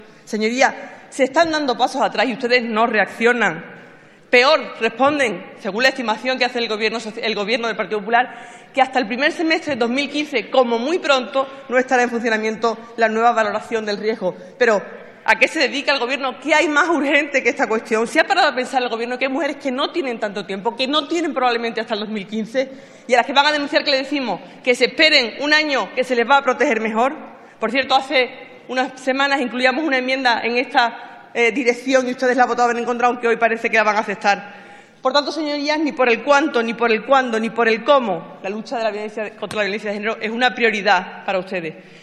Fragmento de la intervención de María José Rodríguez en el Pleno del Congreso del 9/12/2014 en defensa de una moción sobre las medidas que piensa adoptar el gobierno para que la lucha contra la violencia de género se convierta en una de sus prioridades